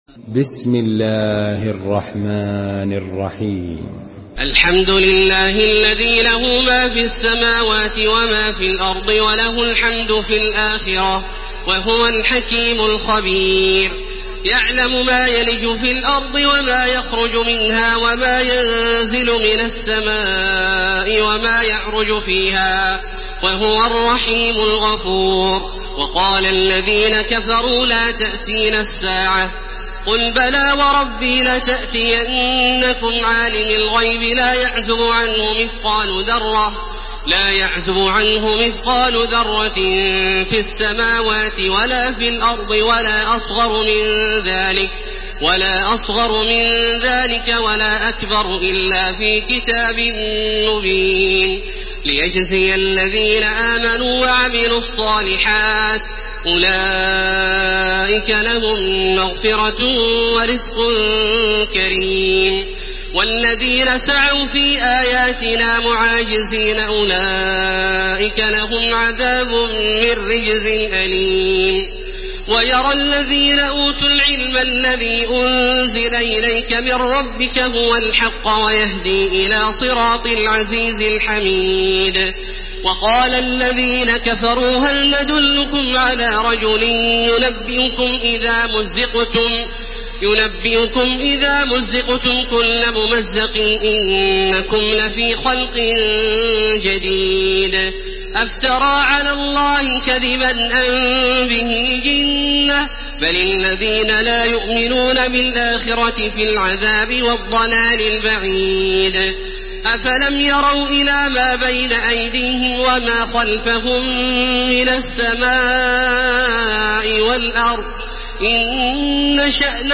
Surah Saba MP3 in the Voice of Makkah Taraweeh 1431 in Hafs Narration
Murattal